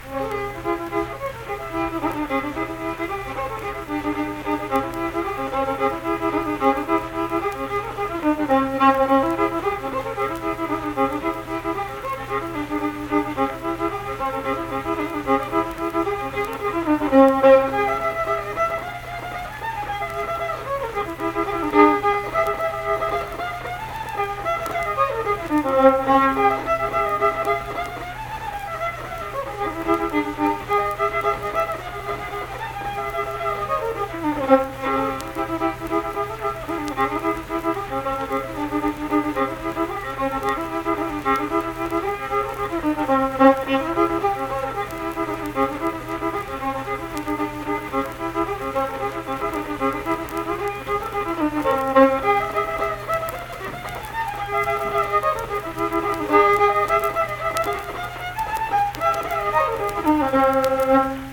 Unaccompanied fiddle music
Verse-refrain 2(2).
Instrumental Music
Fiddle